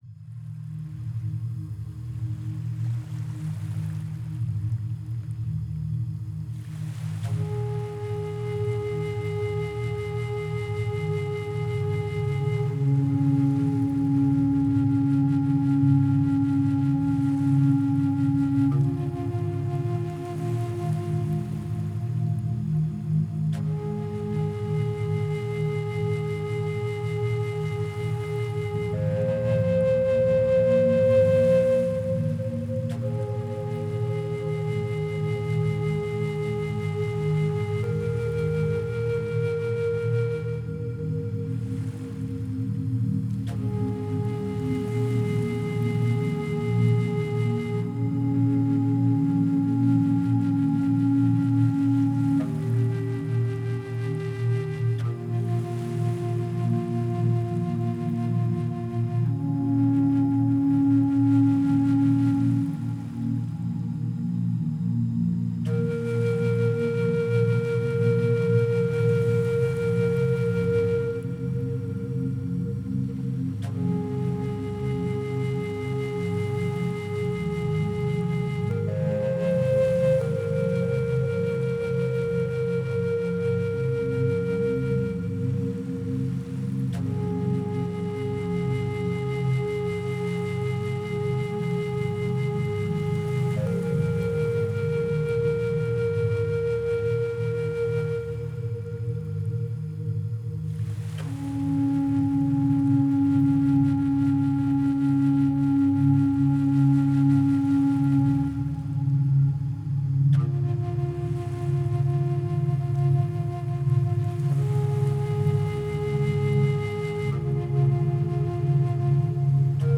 Genre: New Age, Ambient, Relax.